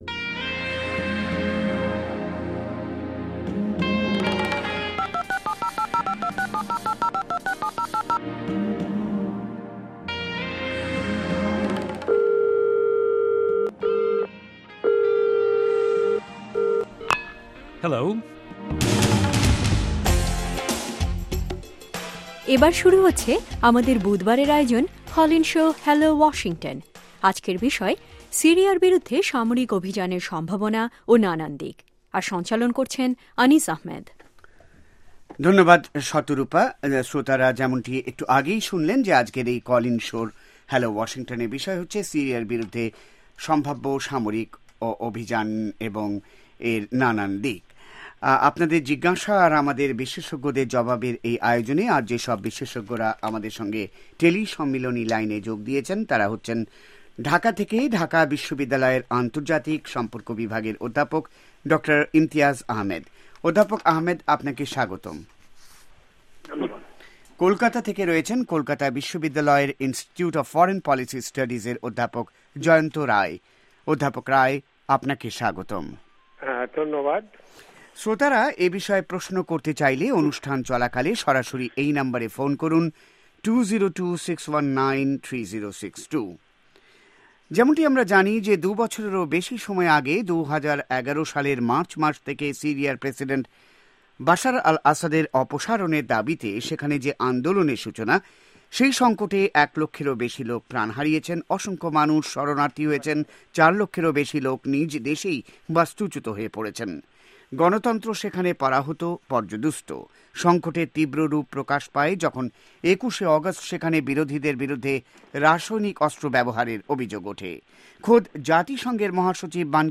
শুনুন কল ইন শো